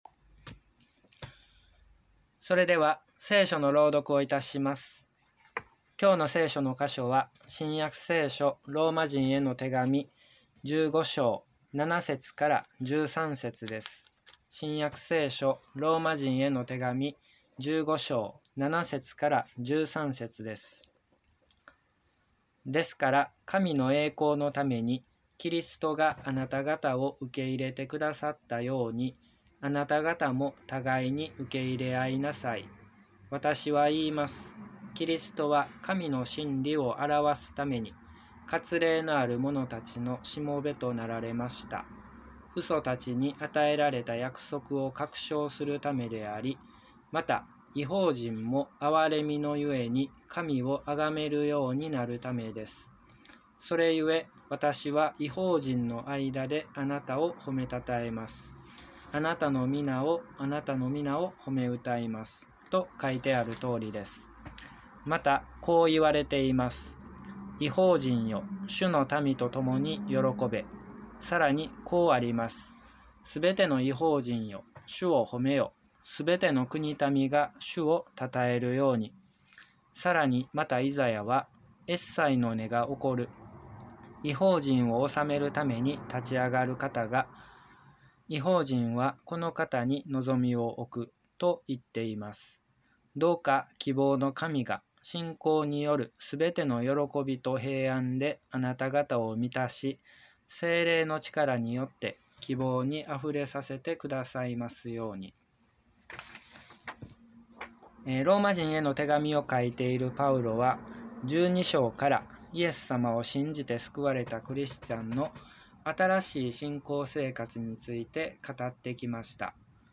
礼拝説教から ２０２１年８月２１日